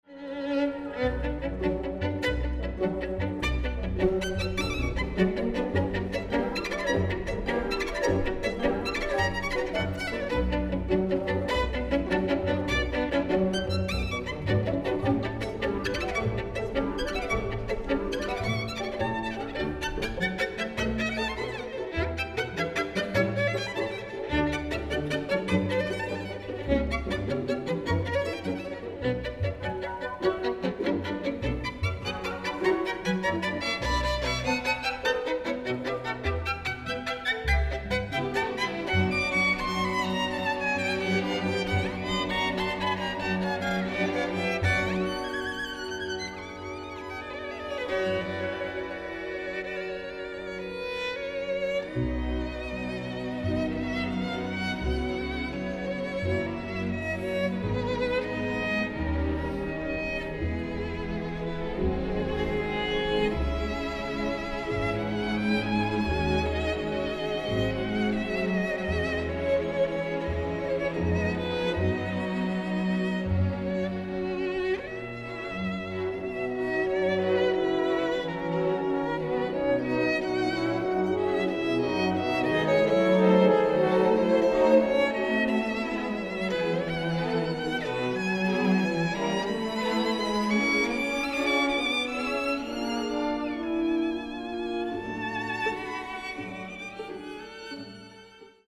eine Live- Aufnahme (DVD+CD) der Superlative